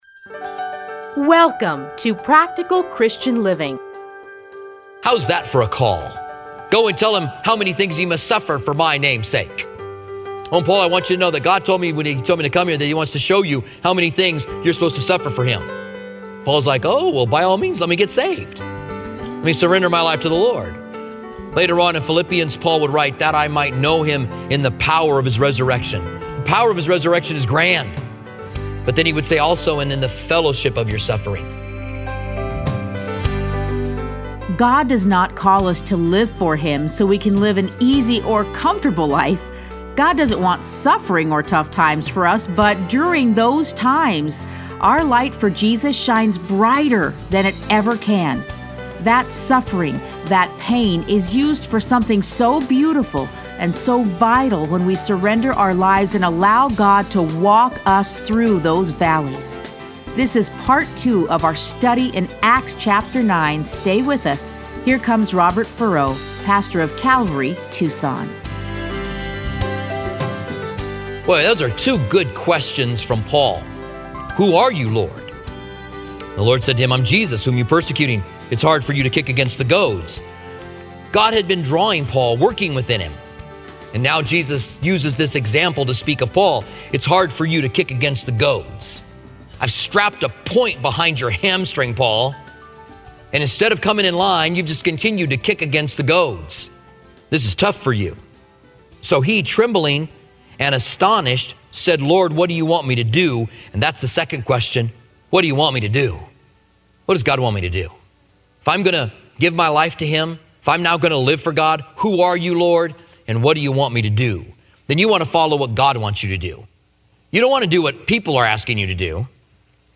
Listen to a teaching from Acts 9.